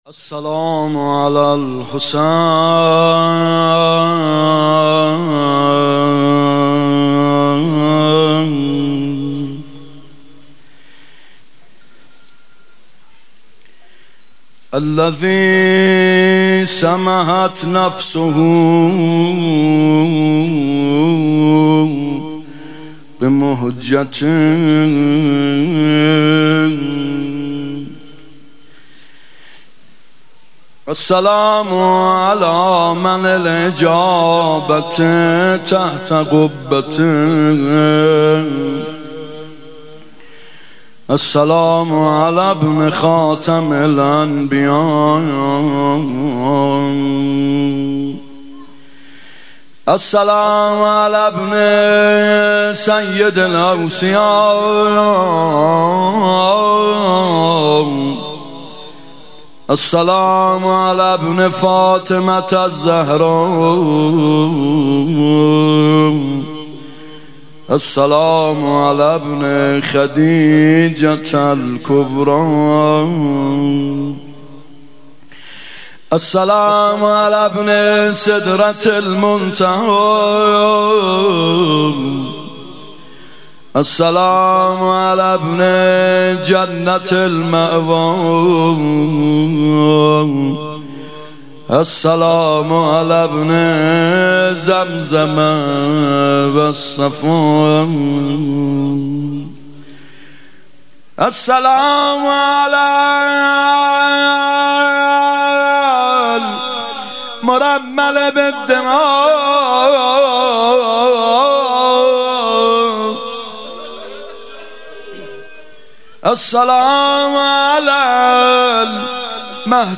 محرم96 هیأت یامهدی عج)